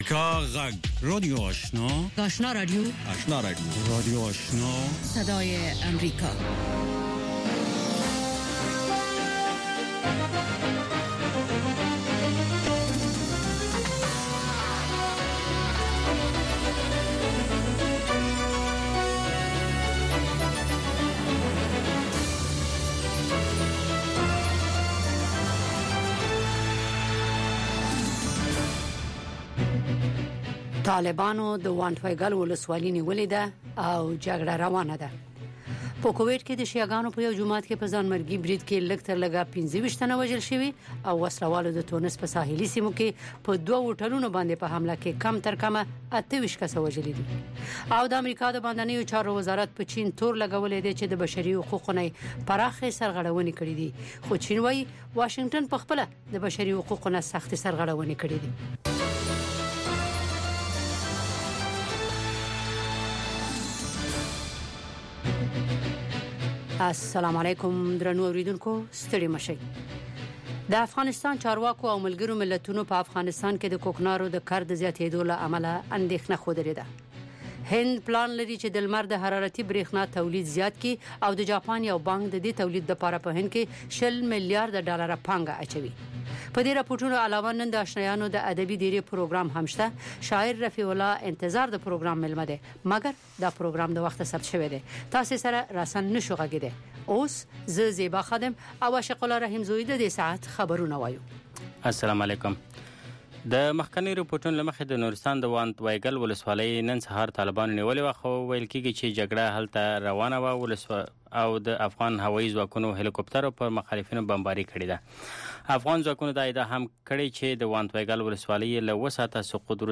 یو ساعته پروگرام: تازه خبرونه، او د نن شپې تېر شوي پروگرامونه ثبت شوي او بیا خپریږي چې د شعر او ادب په گډون هنري، علمي او ادبي مسایل رانغاړي.